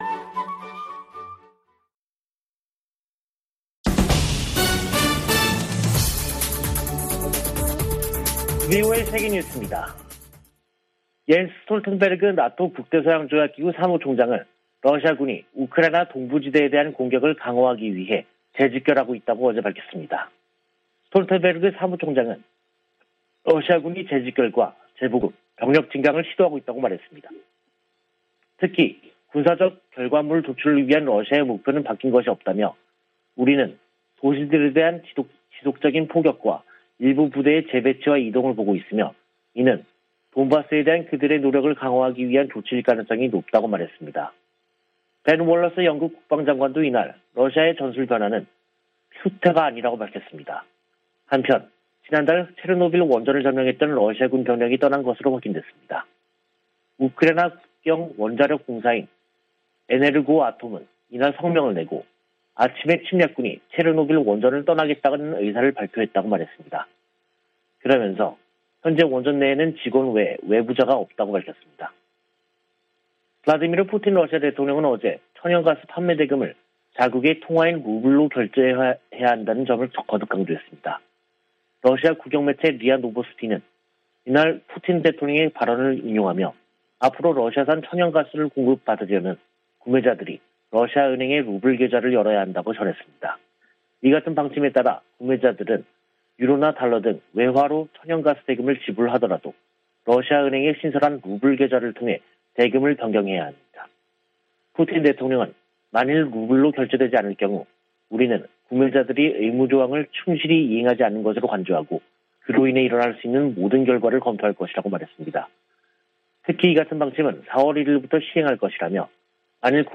VOA 한국어 간판 뉴스 프로그램 '뉴스 투데이', 2022년 4월 1일 2부 방송입니다. 북한이 ICBM 발사에 이어 조기에 핵실험 도발에 나설 것이라는 전망이 나오고 있습니다. 미 국무부는 북한의 추가 도발 가능성을 주시하고 있다면서 추가 압박을 가하는 등 모든 일을 하고 있다고 강조했습니다.